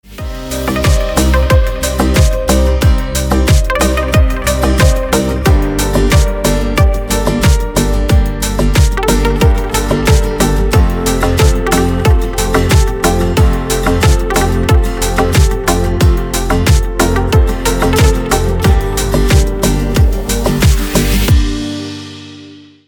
• Песня: Рингтон, нарезка
• Категория: Красивые мелодии и рингтоны